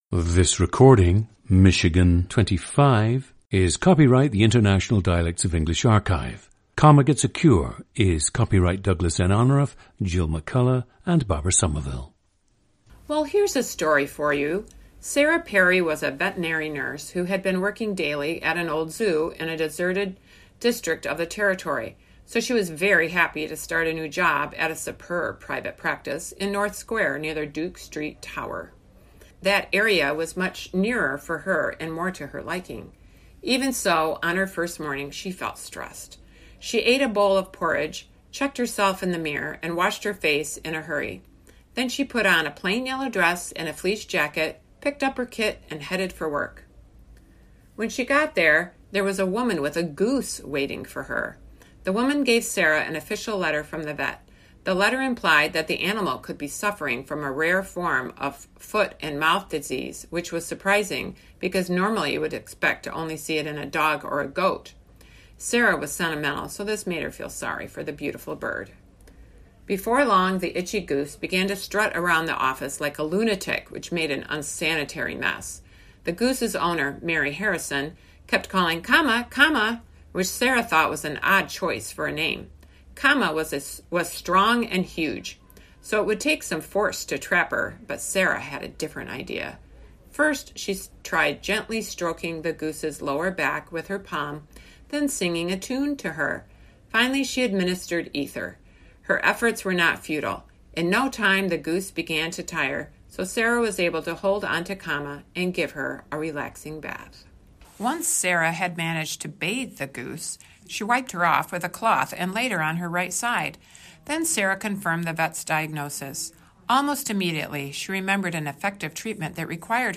GENDER: female
The speaker has never lived outside Michigan.
OTHER INFLUENCES ON SPEECH: none
The speaker demonstrates common Michigan tight front vowel sounds in “lot,” “job,” “odd,” “off,” and “cloth.”
• Recordings of accent/dialect speakers from the region you select.